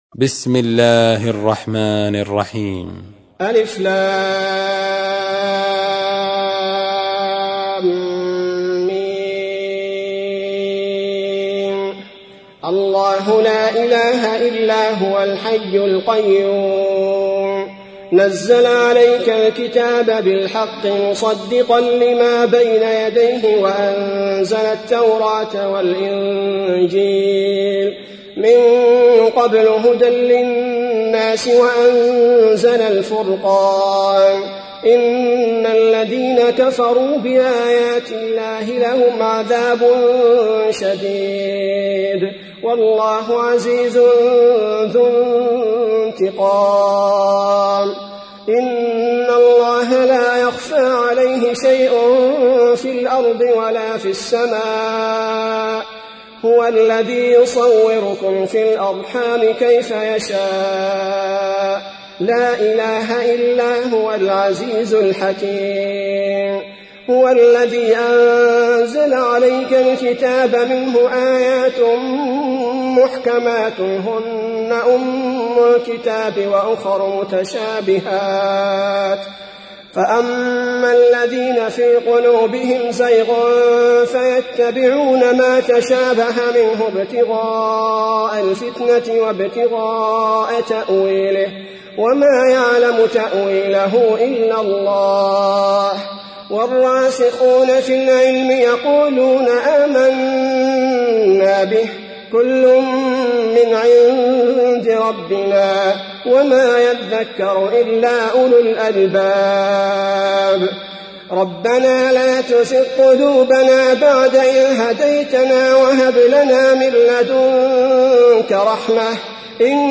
تحميل : 3. سورة آل عمران / القارئ عبد البارئ الثبيتي / القرآن الكريم / موقع يا حسين